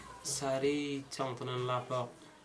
Dialect: Hill